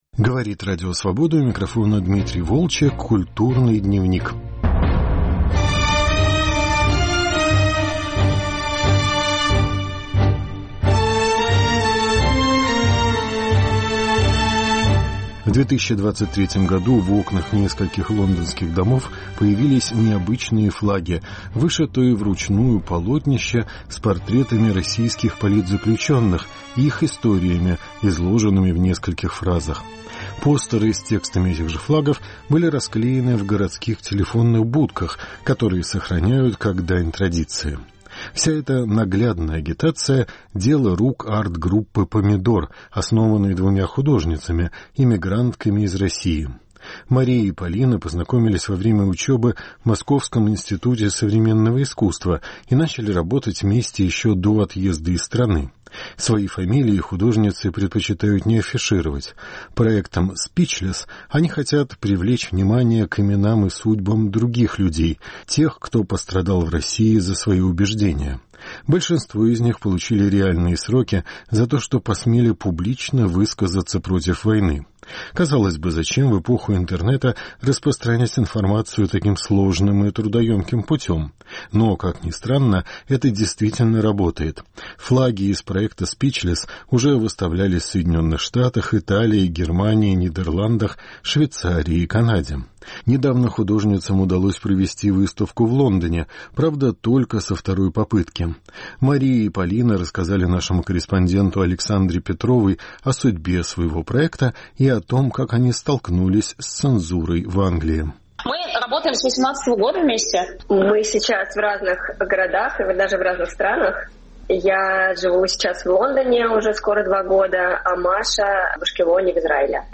Разговор об арт-проекте, посвященном политическим репрессиям в России